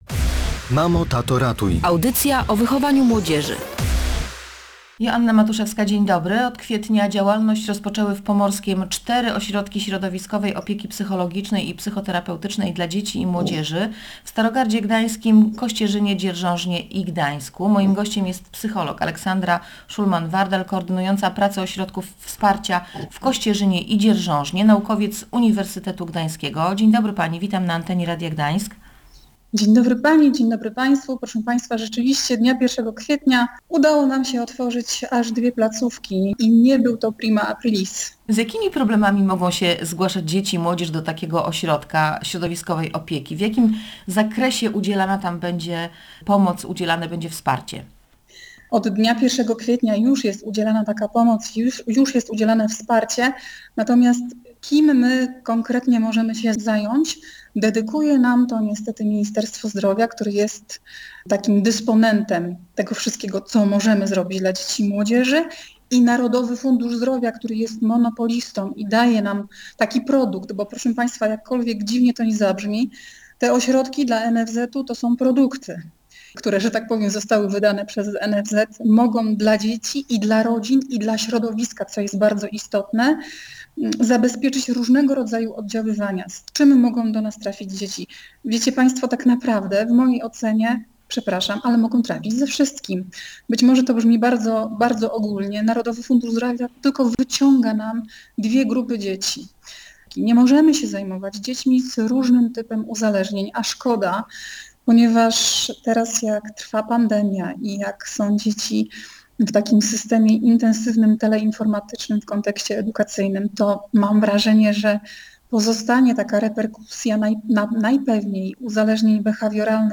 W audycji tłumaczyła kiedy i w jakich sytuacjach można zgłosić się po pomoc.